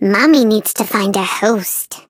flea_start_vo_05.ogg